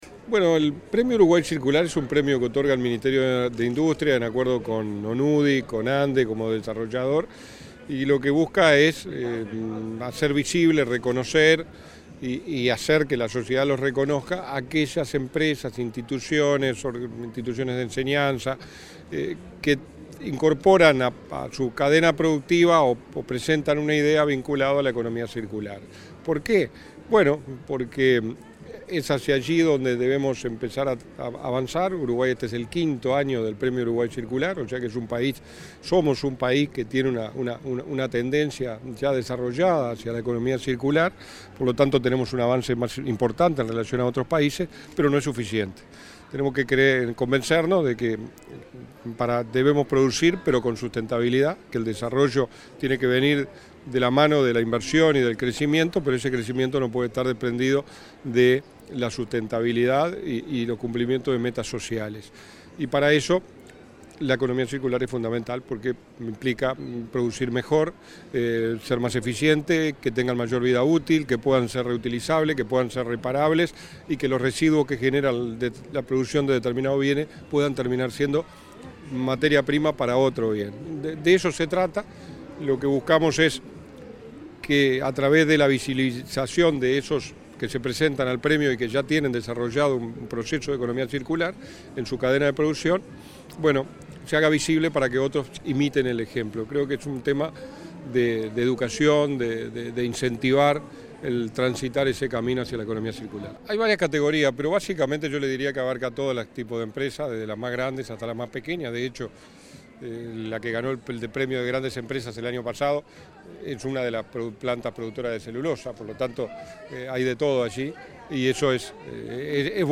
Entrevista al subsecretario de Industria, Walter Verri